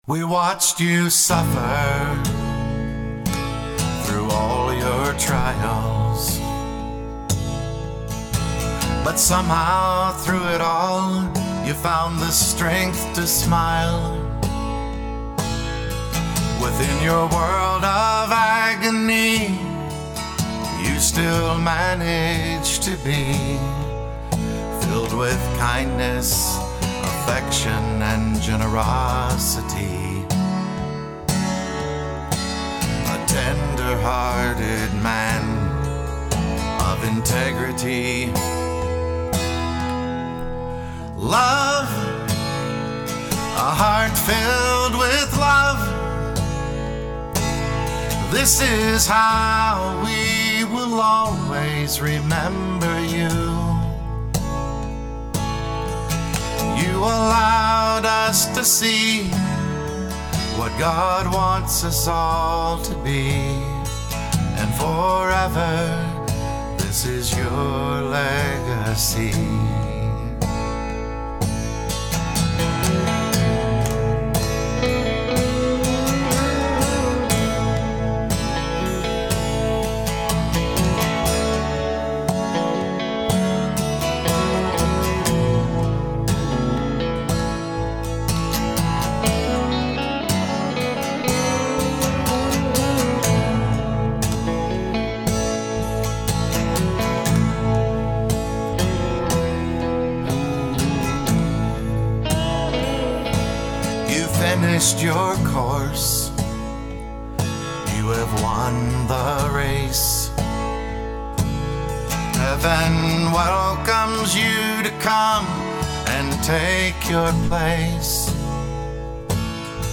This is a brand new CD that we are currently recording.